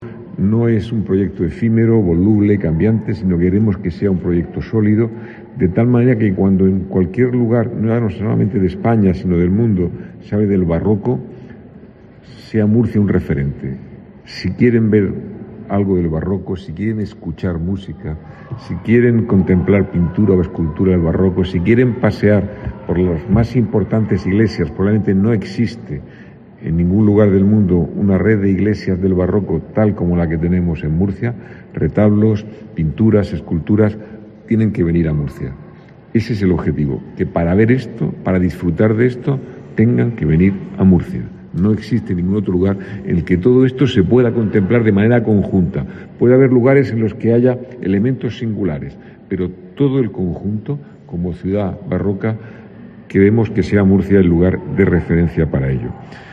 José Ballesta, alcalde de Murcia